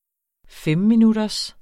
Udtale [ ˈfεmmiˌnudʌs ]